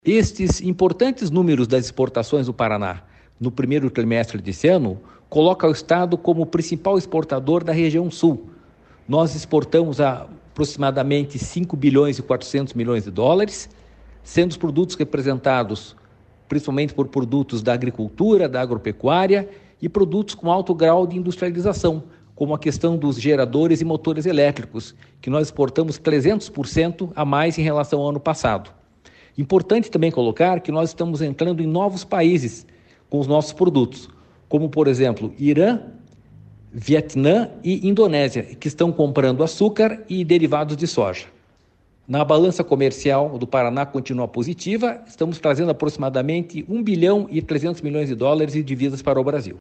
Sonora do diretor-presidente do Ipardes, Jorge Callado, sobre as exportações do Paraná no 1º trimestre do ano